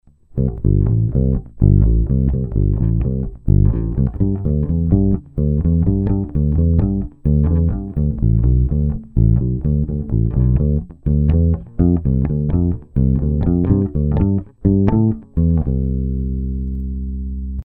Mizerný nahrávky spíchnutý za pár minut.. Tempo nic moc, přeznívání atd. Není to pořádný A/B porovnání - ani dvě z nich nemaj stejný struny, 3*flat, 1*round..
olše/brazilskej palisandr/krkovej snímač/bez tónovky